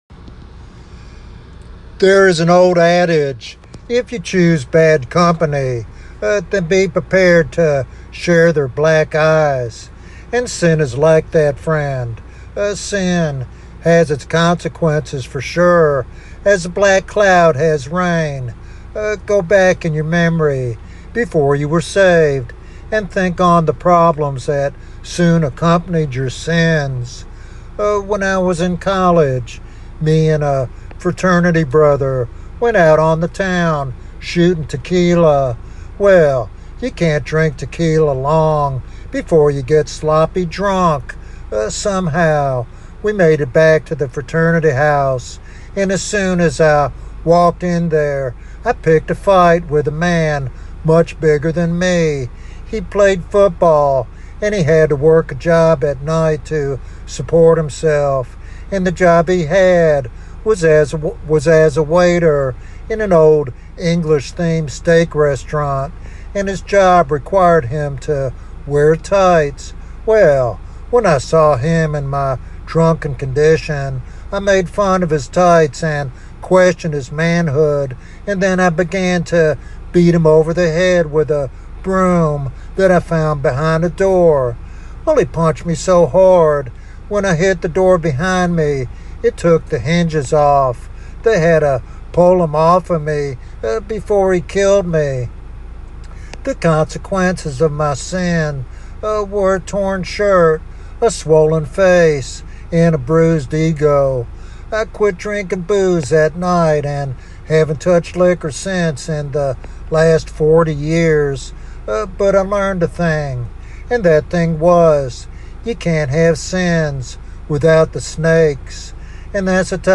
In this powerful sermon